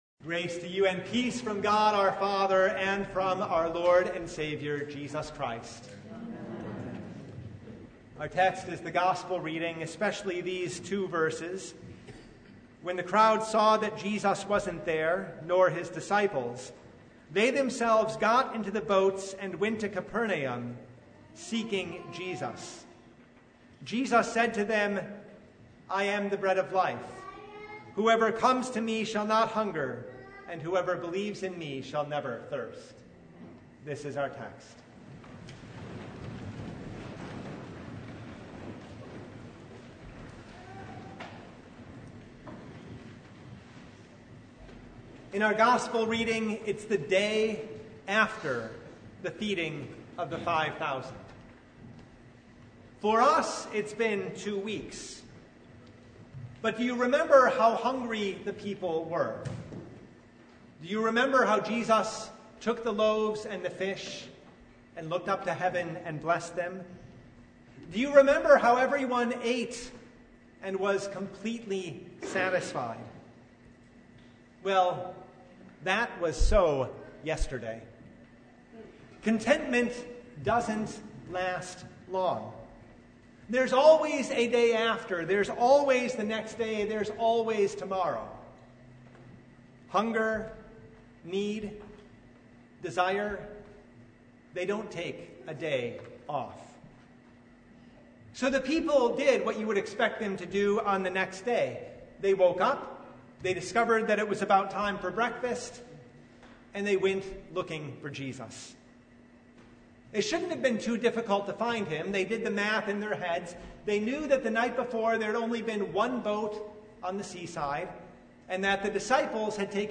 John 6:22–35 Service Type: Sunday “You are seeking Me